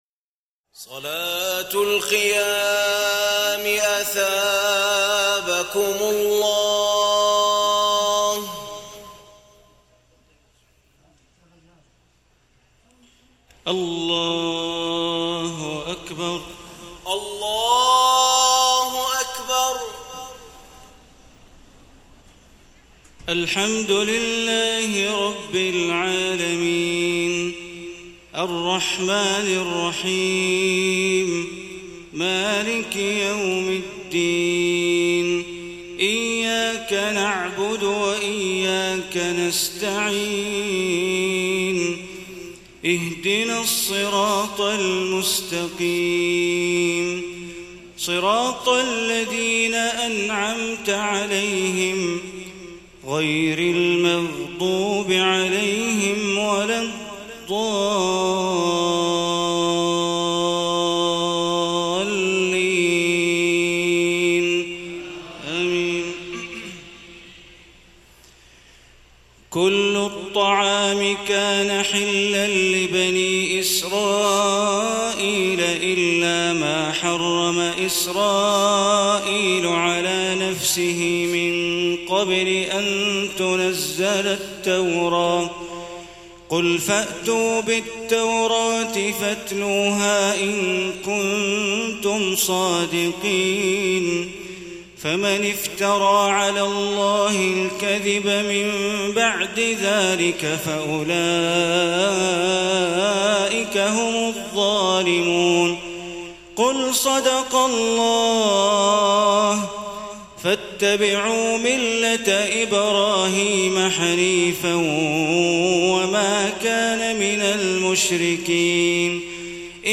تهجد ليلة 24 رمضان 1434هـ من سورة آل عمران (93-185) Tahajjud 24 st night Ramadan 1434H from Surah Aal-i-Imraan > تراويح الحرم المكي عام 1434 🕋 > التراويح - تلاوات الحرمين